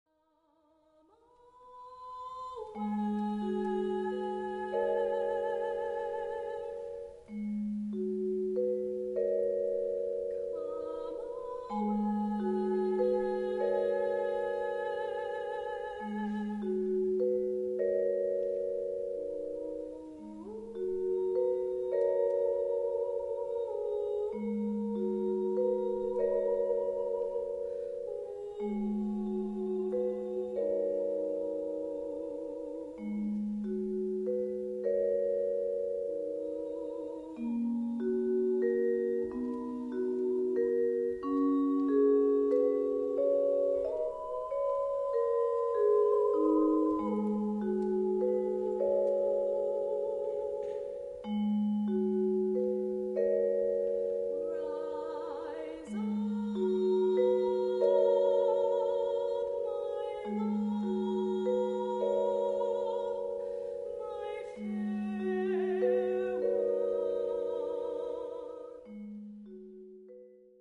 a ballad of unrequited love.
Requires 4 mallets. adv. high school/college.